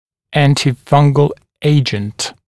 [ˌæntɪ’fʌŋgl ‘eɪʤənt][ˌэнти’фанг(э)л ‘эйджэнт]противогрибковое средство